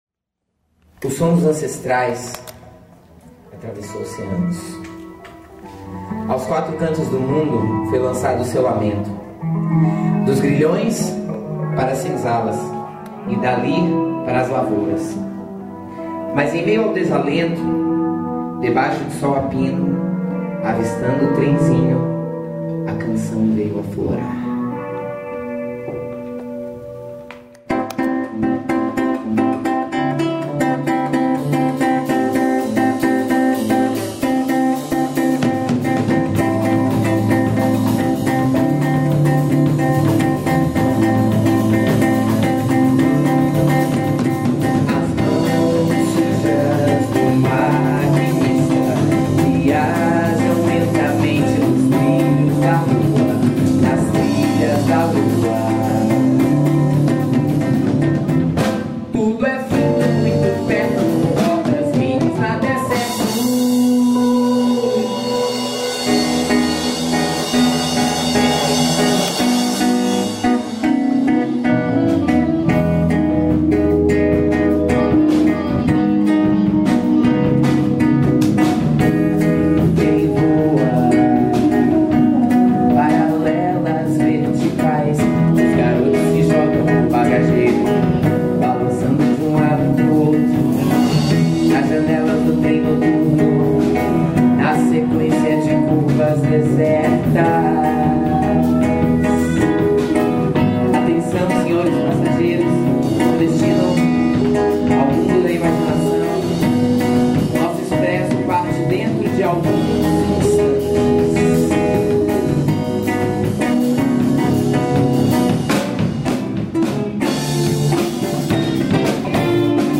EstiloSamba Rock